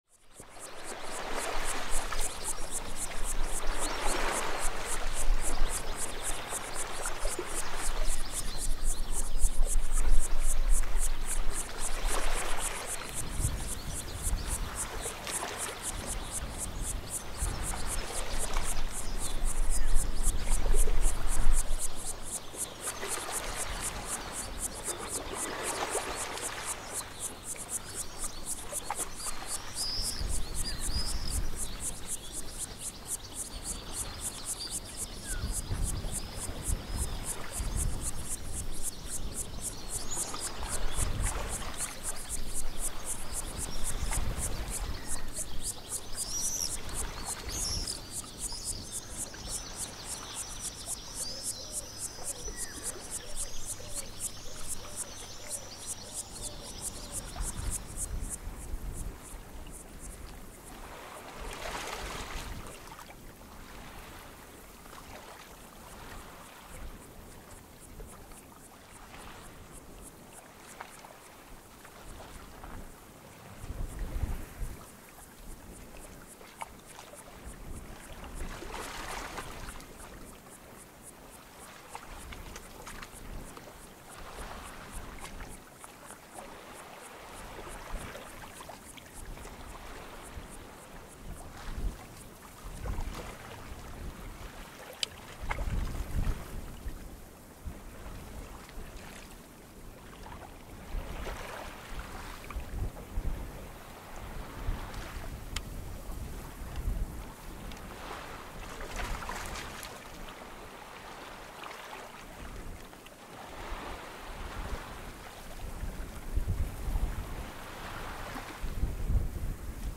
صدای امواج دریا: